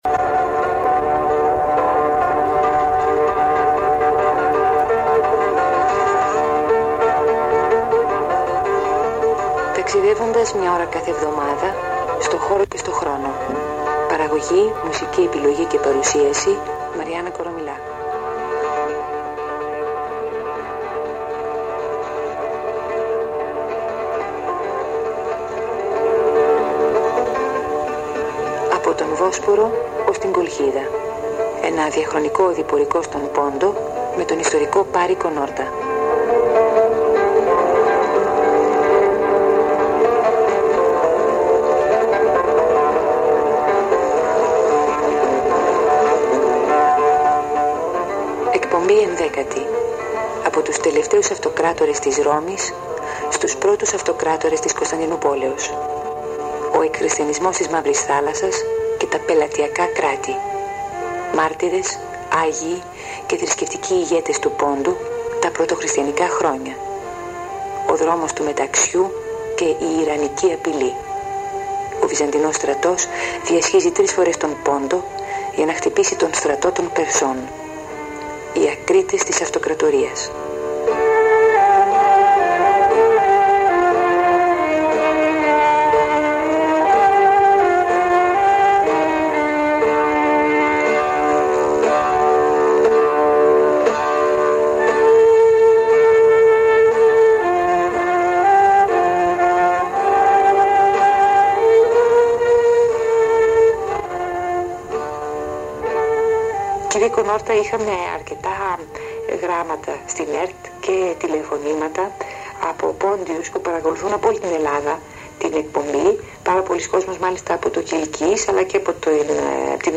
Υπότιτλος Ταξιδεύοντας στον χώρο και τον χρόνο Είδος Audio / Ακουστικό Χαρακτηρισμός Χαρακτηρισμός Ραδιοφωνική εκπομπή.